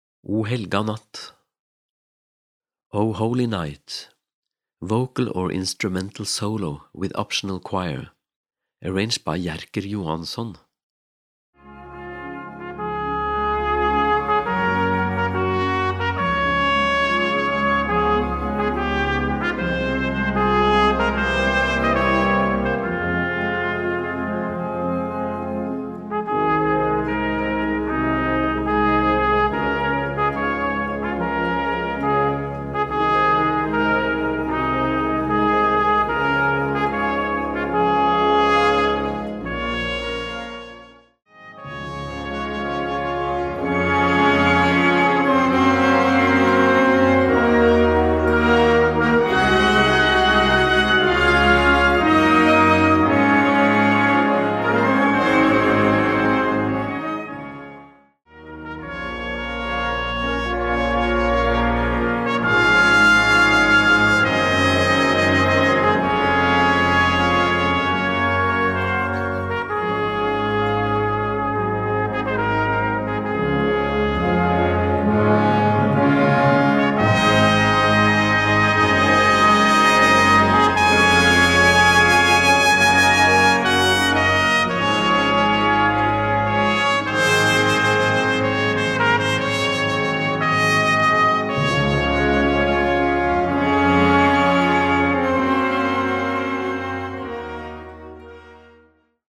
Gattung: für Gesang, Chor, Trompete, Posaune oder Euphonium
Besetzung: Blasorchester